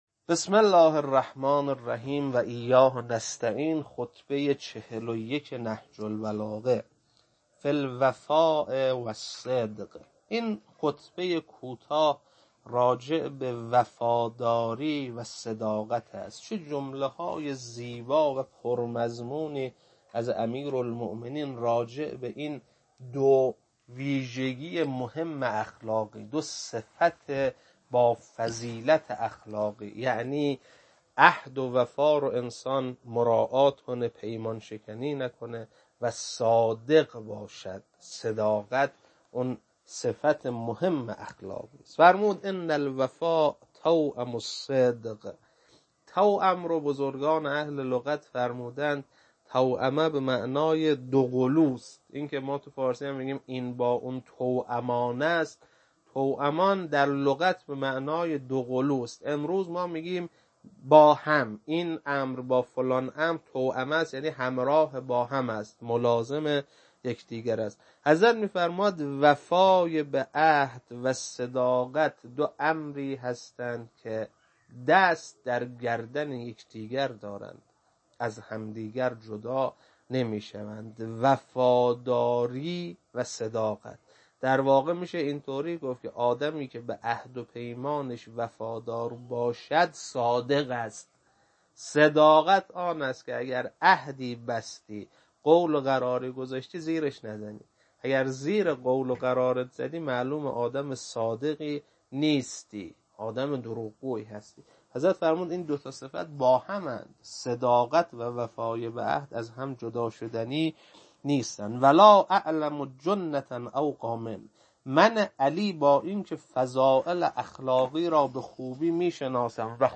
خطبه 41.mp3
خطبه-41.mp3